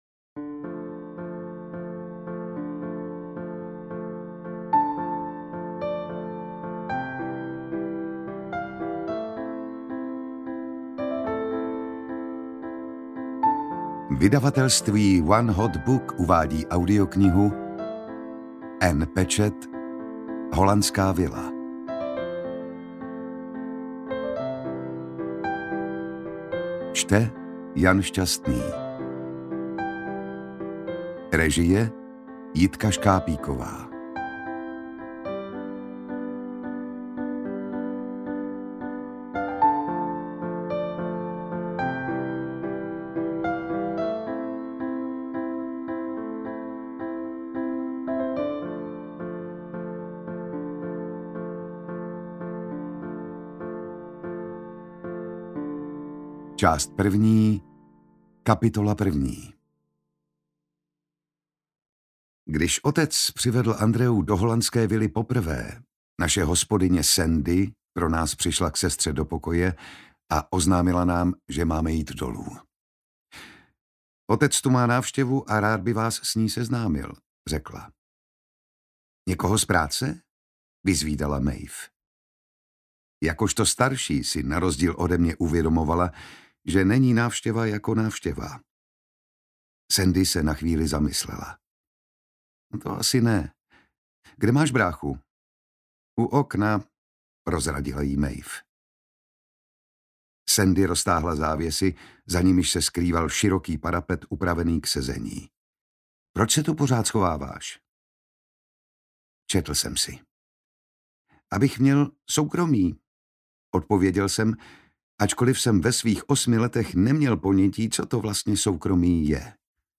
Interpret:  Jan Šťastný
AudioKniha ke stažení, 39 x mp3, délka 12 hod. 28 min., velikost 674,0 MB, česky